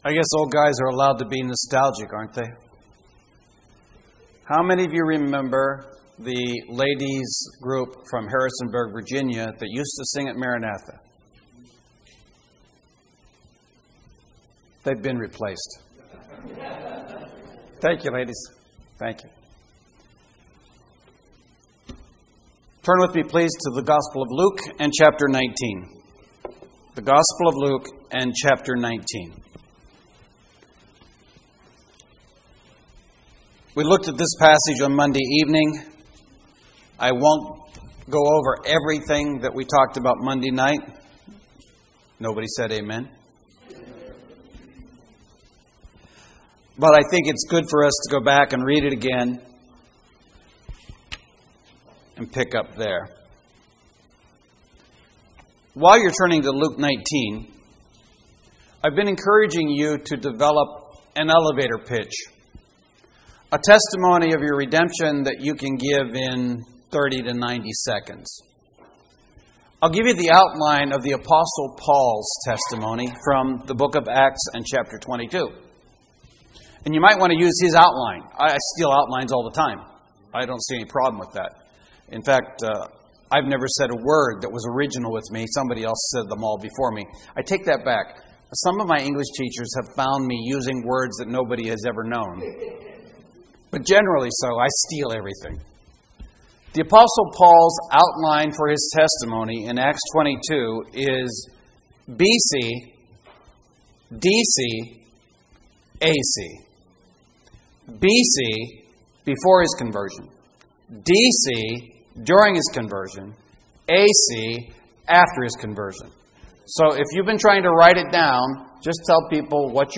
Series: 2012 August Conference Session: Evening Session